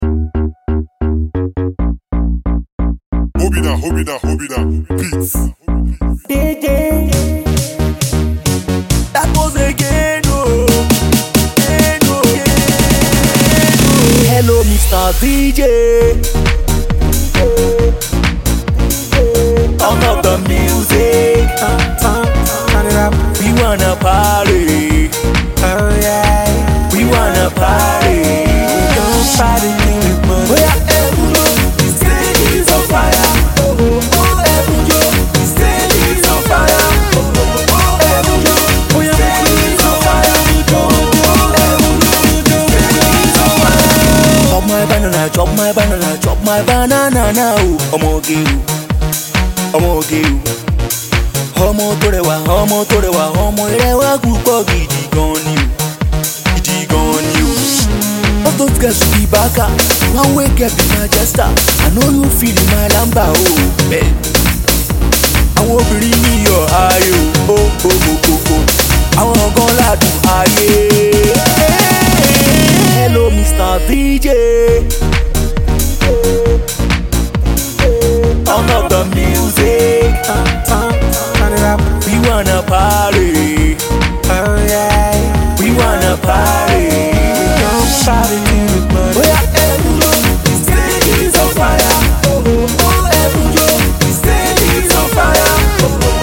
energetic talented Afro-Pop singer
strong powerful vocal ability
Rocky tune